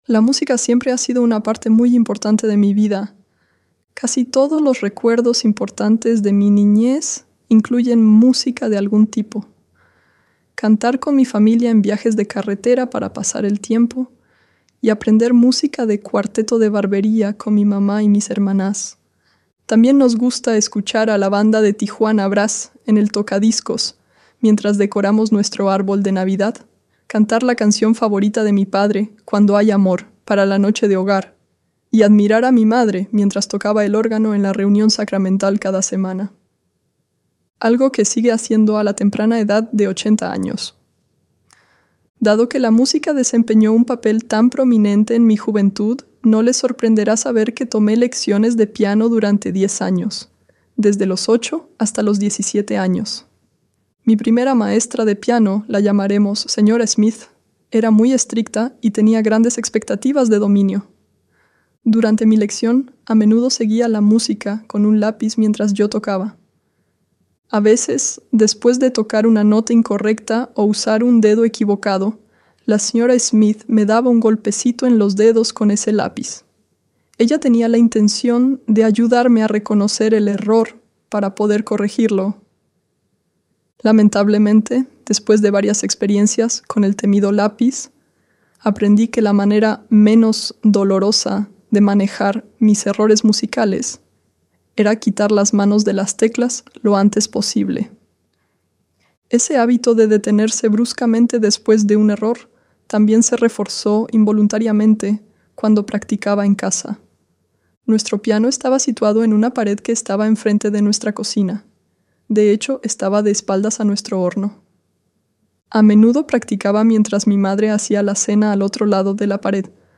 En su discurso compartió cómo sus experiencias personales le han enseñado a confiar en los demás, especialmente en el Salvador, y a no rendirse nunca.